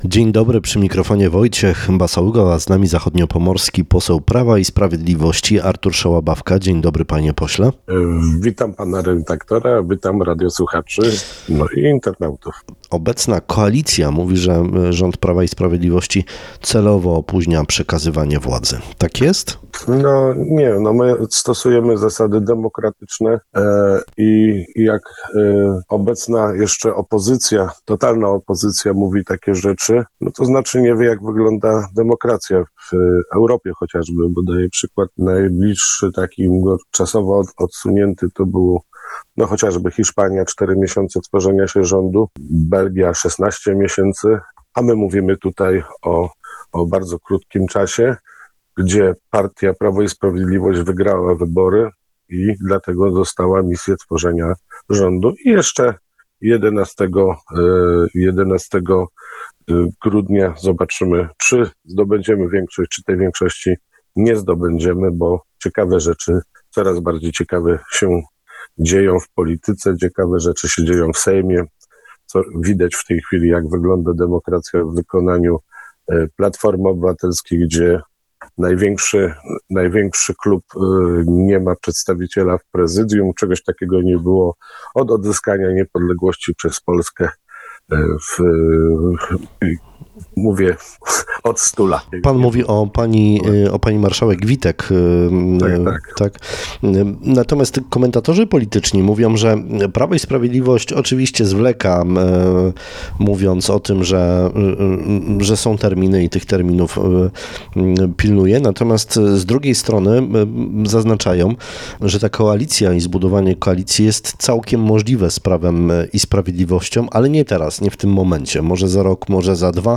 Tak jak nasz dzisiejszy gość Rozmowy Dnia Artur Szałabawka.